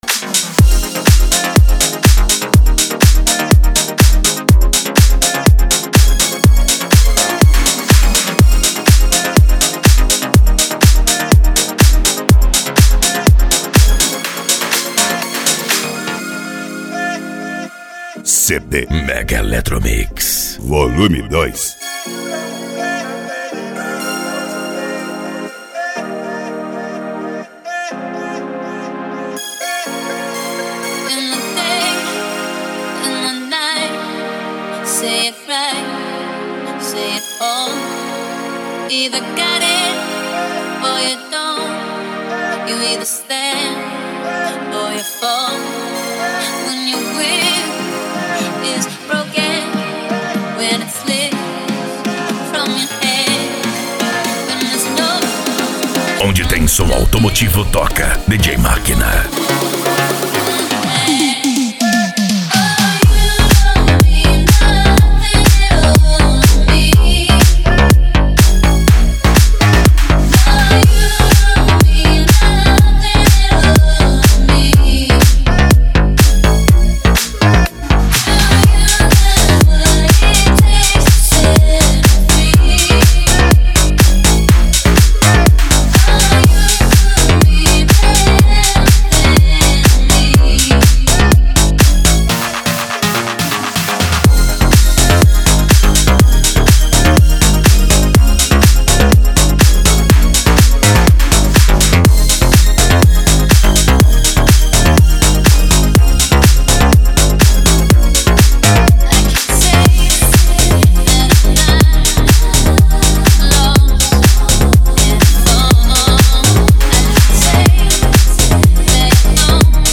Electro House
Minimal
Psy Trance
Remix